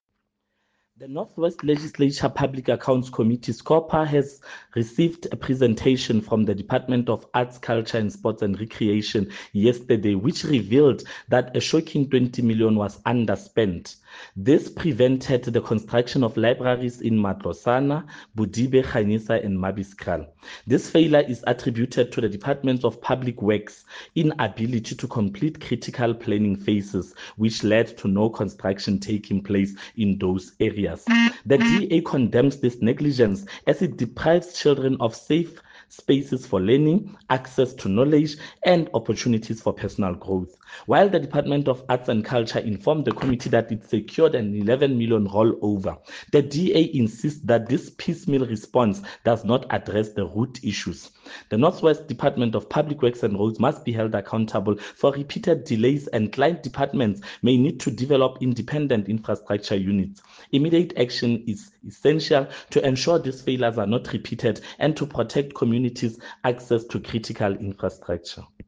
Note to Broadcasters: Please find attached soundbite in
English by Freddy Sonakile